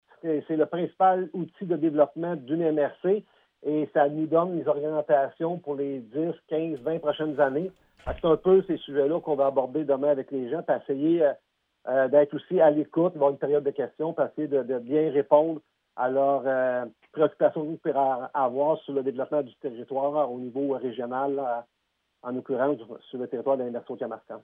L’un des grands chantiers actuellement en cours est le renouvellement du schéma de l’aménagement du territoire comme l’explique Paul Sarrazin : MRC H-Y, dejeuner prefet, 10.12.24_Sarrazin, clip Parmi les autres sujets qui seront abordés lors de cette matinée, notons le vélo, le transport collectif et le transport adapté.